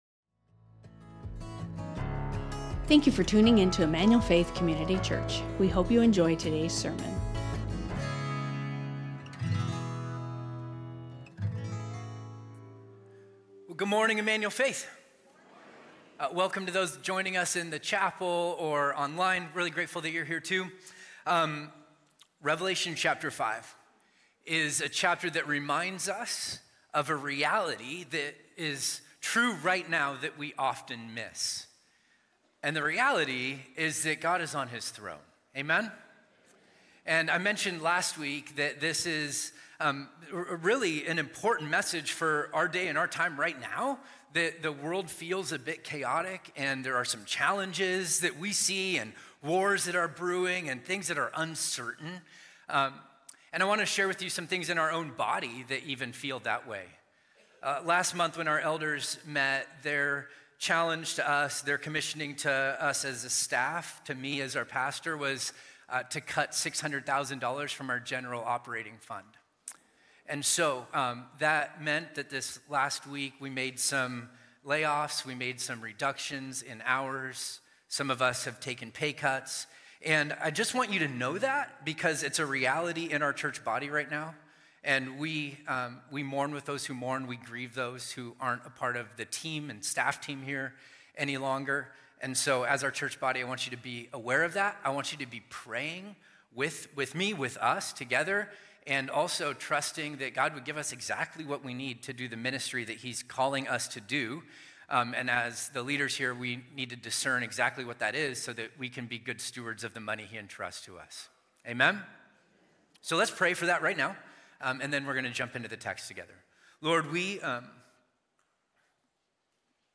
Emmanuel Faith Sermon Podcast From Weeping to Worship Jun 30 2025 | 00:43:27 1x 00:00 / 00:43:27 Subscribe Share Spotify Amazon Music RSS Feed Share Link Embed